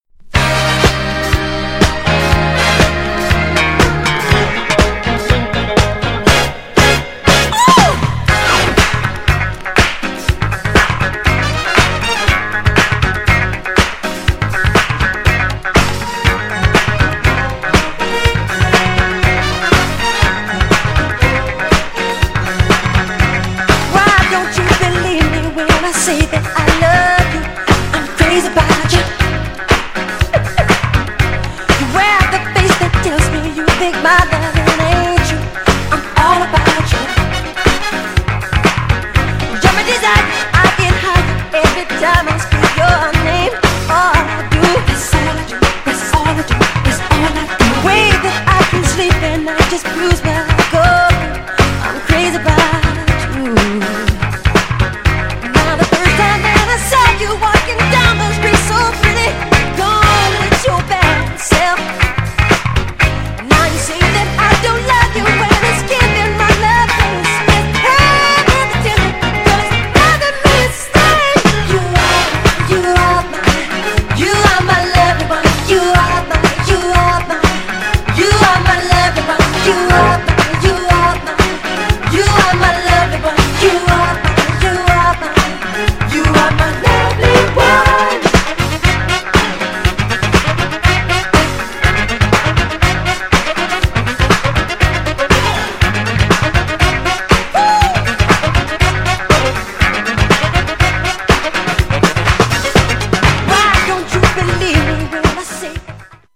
サビのコーラスが印象的!!
GENRE Dance Classic
BPM 126〜130BPM
ドラマティック
熱いボーカル